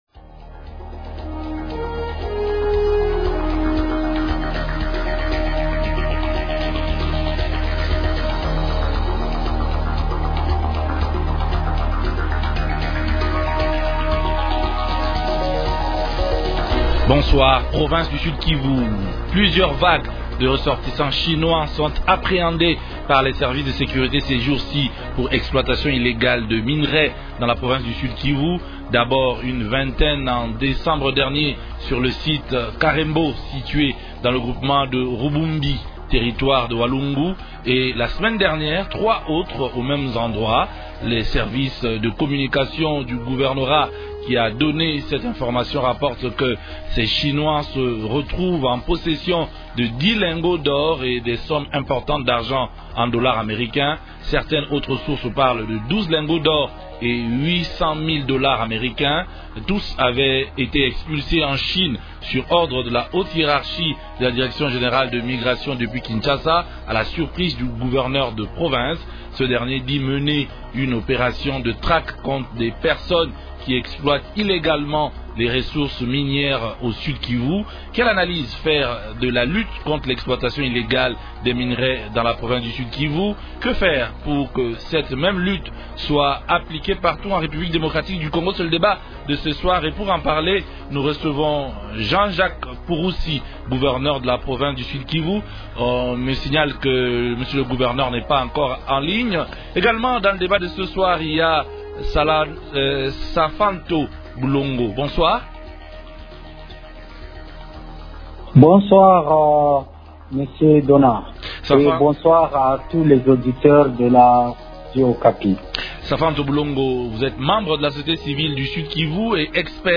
Actualité politique de ce soir
-Quelle analyse faire de la lutte contre l'exploitation illégale des minerais au Sud-Kivu ? -Cette approche ne peut-elle pas être étendue à d’autres provinces de la RDC ? Invités : -Jean-Jacques Purusi, gouverneur de la province du Sud-Kivu.